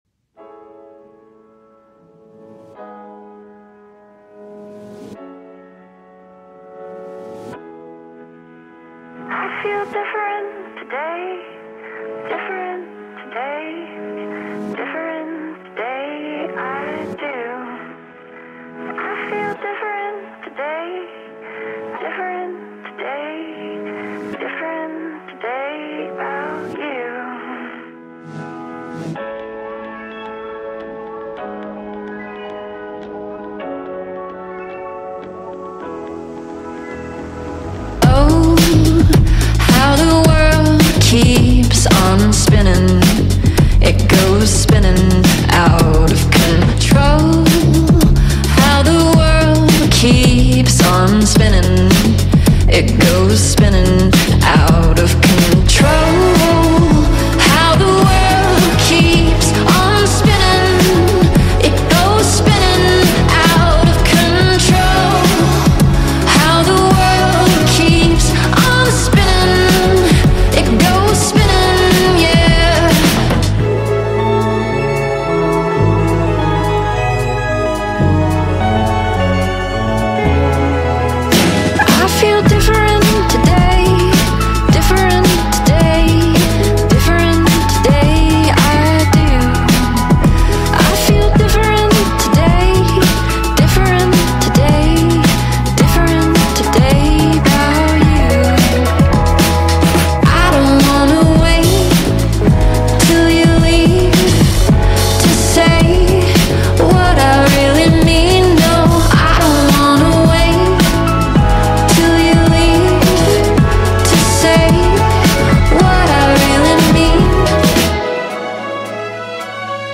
индитроника-песня из трейлера к фильму